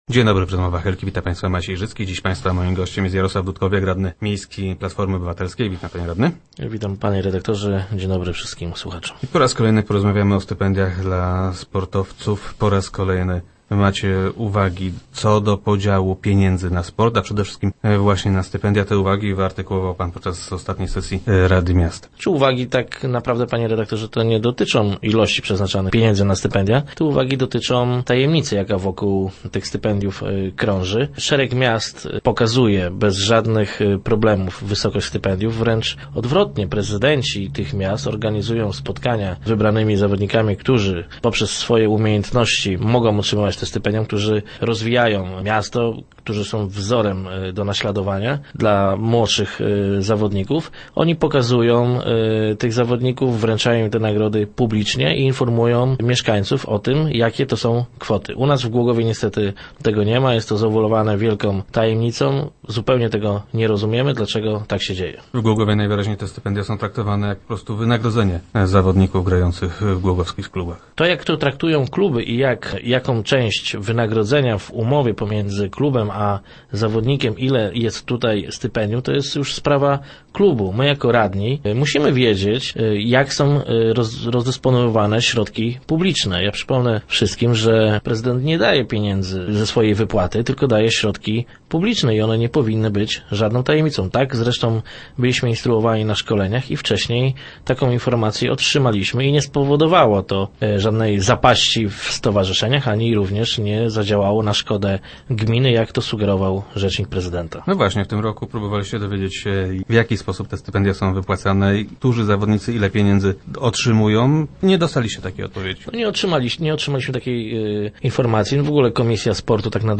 - Dlaczego nie możemy się dowiedzieć, ile pieniędzy dostają – pyta radny Jarosław Dudkowiak, który był gościem Rozmów Elki.